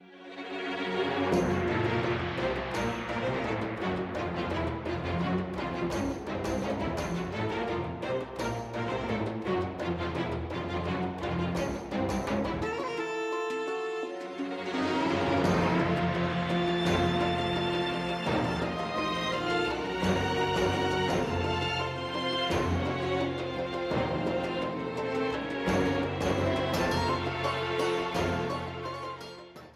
A yellow streamer theme
Ripped from the game
clipped to 30 seconds and applied fade-out